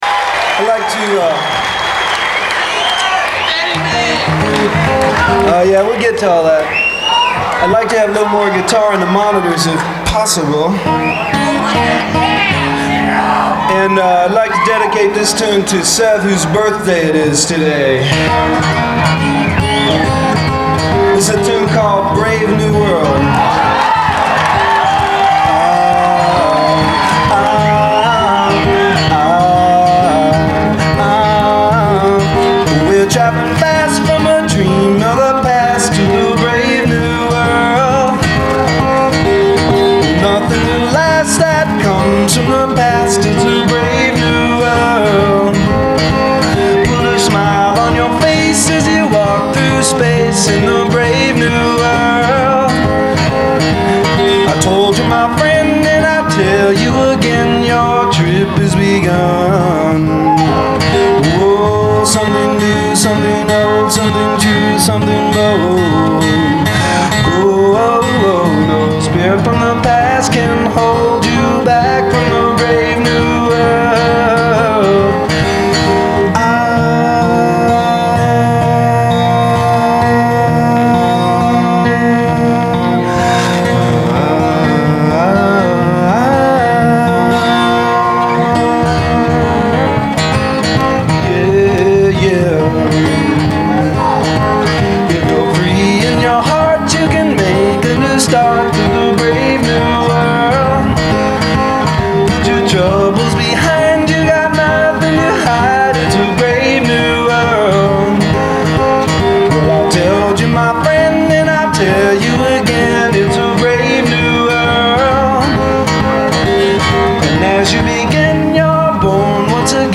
Genre : Rock
Live - Los Angeles, CA, May 20, 1972